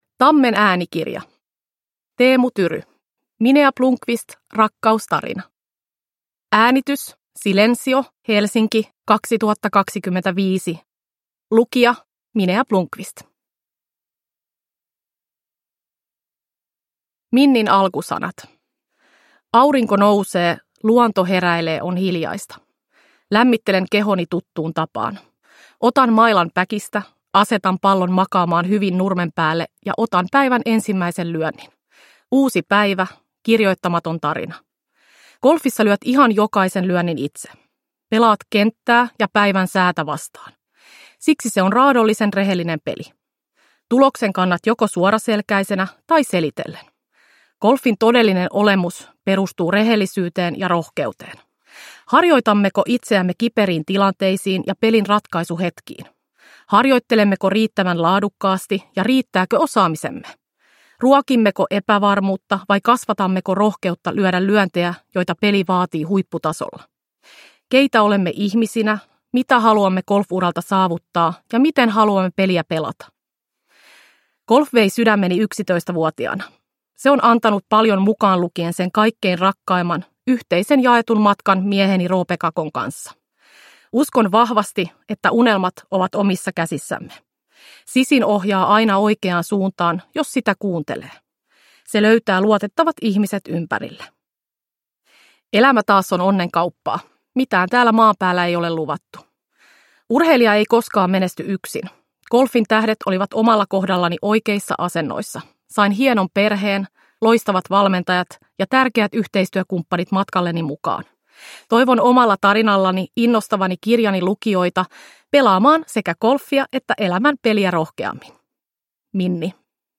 Minea Blomqvist – Ljudbok
Uppläsare: Minea Blomqvist